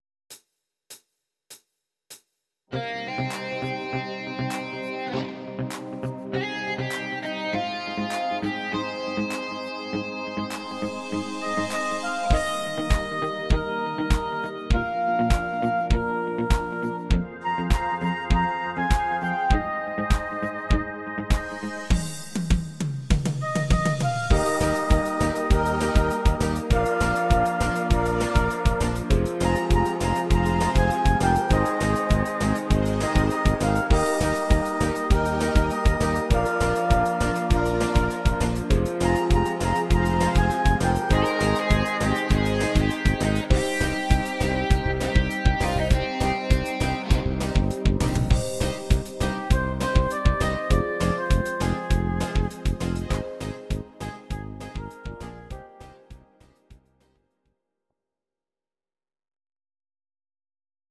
Audio Recordings based on Midi-files
German, 2010s